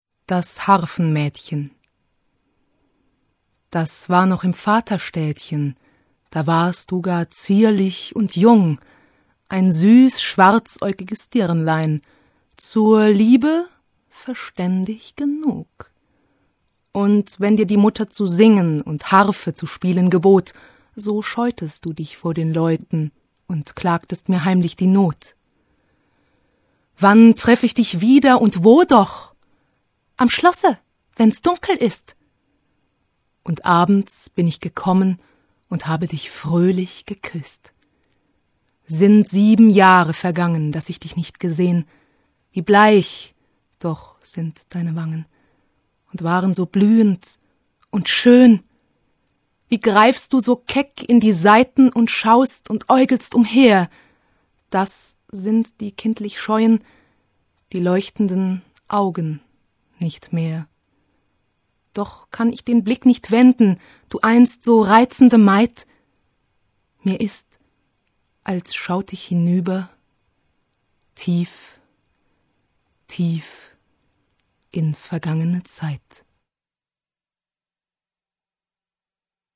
deutsche Sprecherin, Schauspielerin und Sängerin.
Kein Dialekt
german female voice over artist